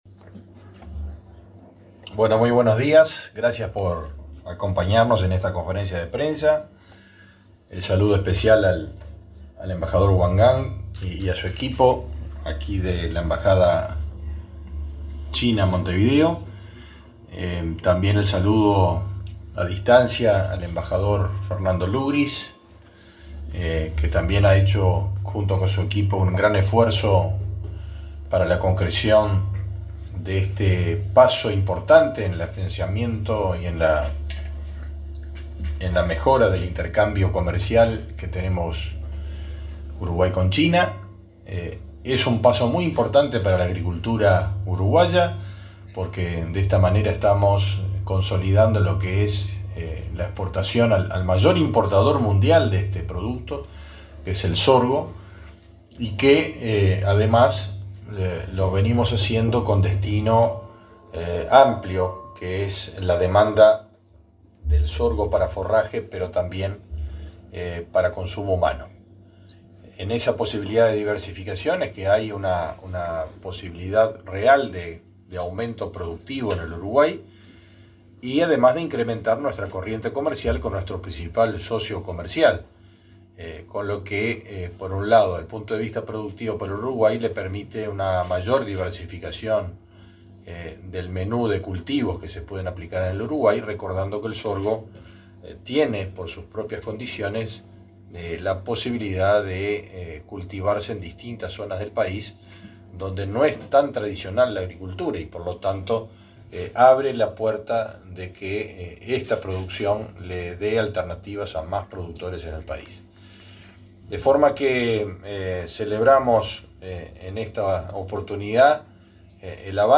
Palabras de autoridades en acto del Ministerio de Ganadería
El ministro de Ganadería, Fernando Mattos, y el embajador de China, Wang Gang, señalaron la importancia de la firma de un protocolo para exportar